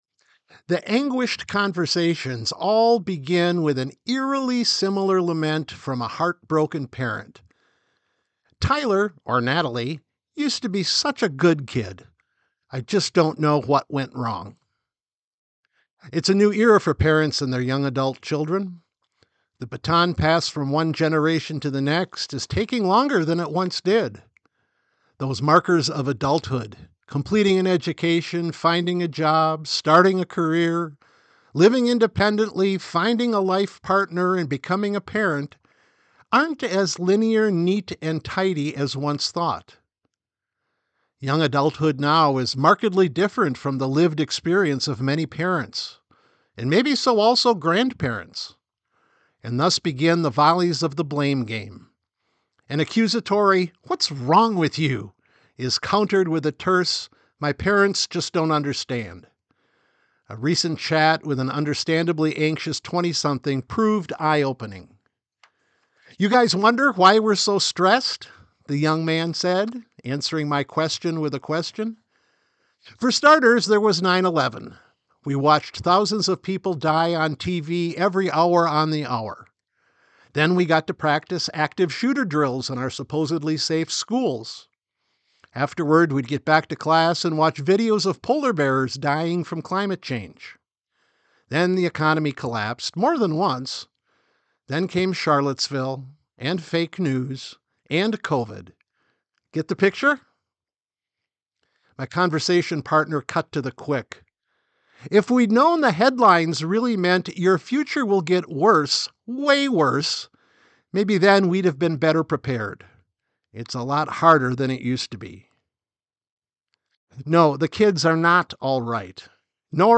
sermon_final-1.mp3